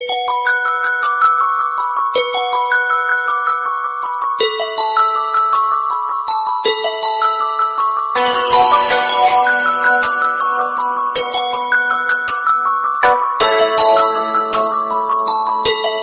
Categoria Telefone